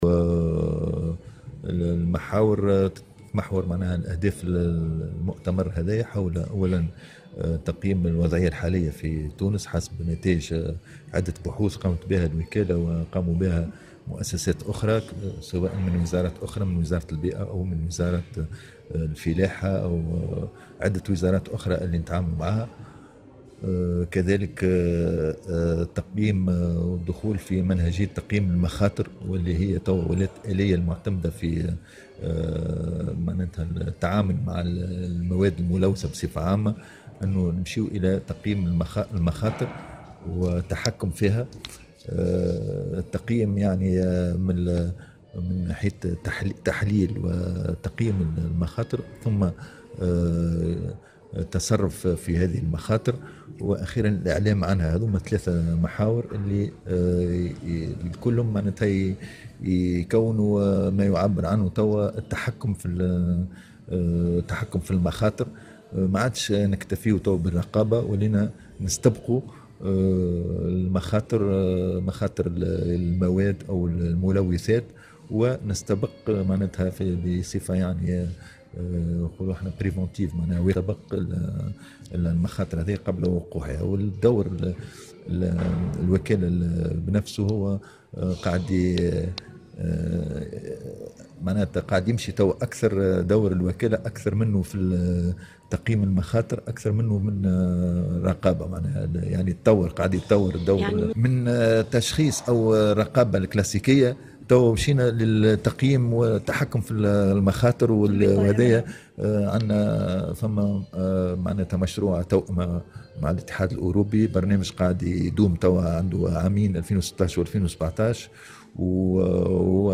أكد شهاب بن ريانة مدير عام الوكالة الوطنية للمراقبة الصحية و البيئية وصحة المنتجات في تصريح لمراسلة الجوهرة "اف ام" اليوم على هامش الملتقى الوطني الثالث و السلامة الصحية حول الملوثات المعدنية و تأثيرها على البيئة و الصحة أن أهداف هذا الملتقى تتمحور حول تقييم الوضعية الحالية في تونس حسب نتائج بحوث قامت بها الوكالة و عدة وزارات أخرى.